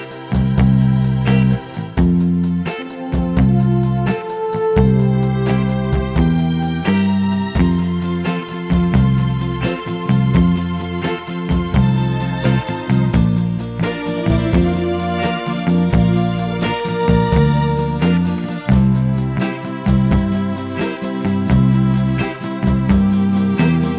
No Backing Vocals Crooners 3:21 Buy £1.50